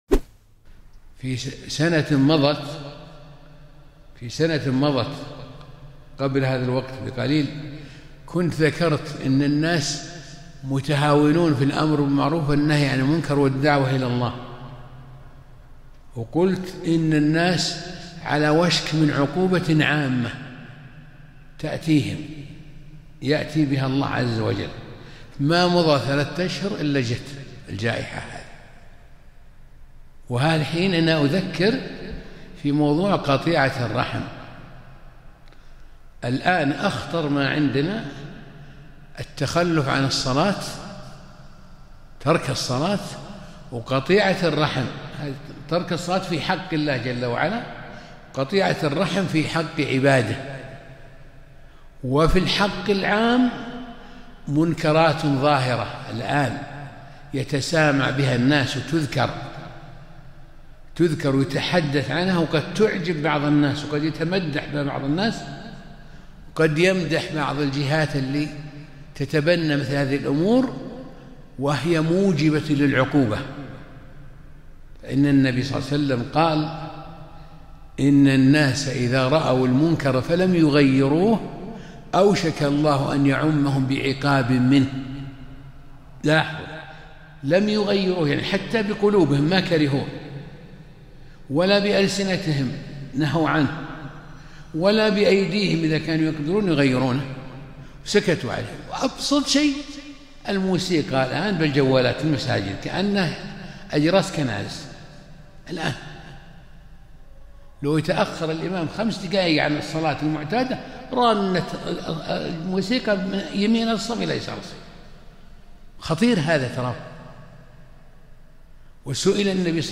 كلمة مهمة - تذكرون ما حذرتكم منه قبل كورونا ! فوالله ثم والله إذا استمريتم فانتظروا عقوبات أشد